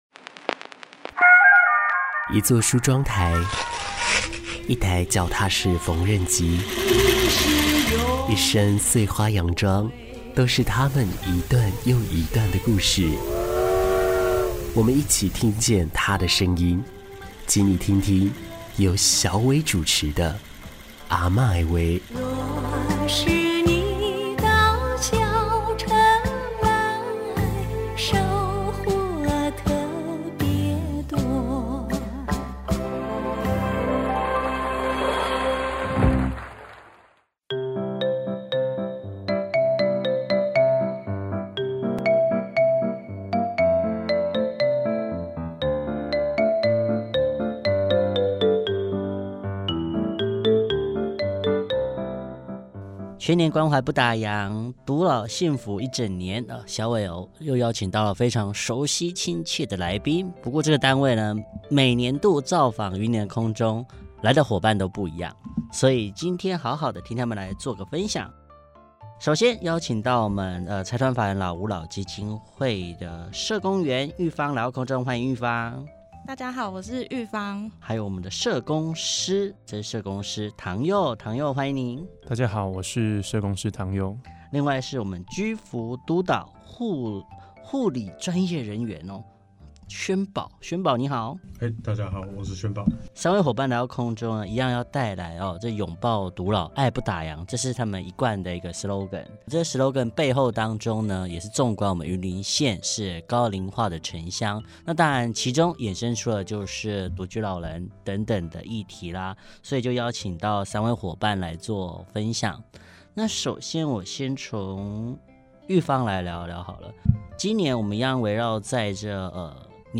首集邀請三位來自第一線的溫柔力量，分享他們與長輩之間的暖心故事：